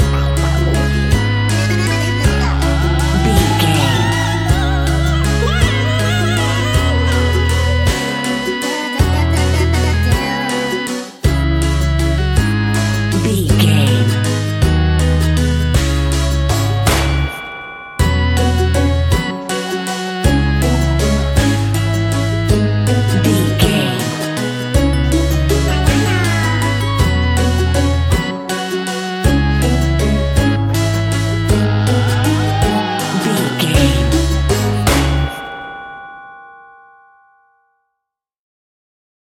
Aeolian/Minor
C#
ominous
eerie
acoustic guitar
percussion
strings
spooky